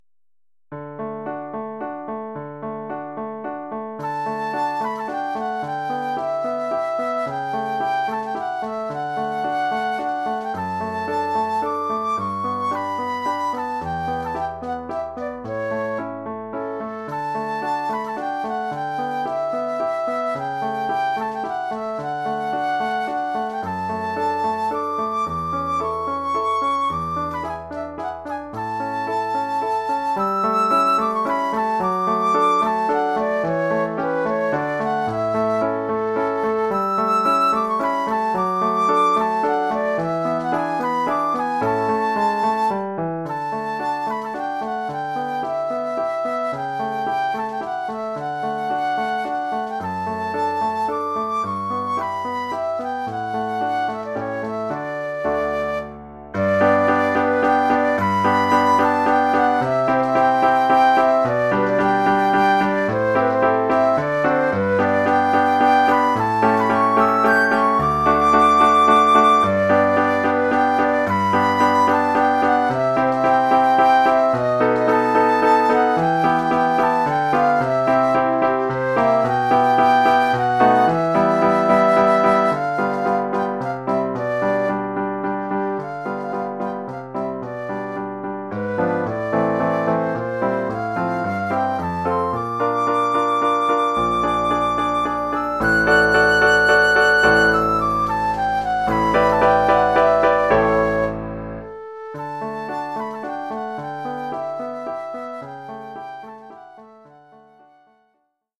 Formule instrumentale : Flûte et piano
Oeuvre pour flûte et piano.
Niveau : élémentaire.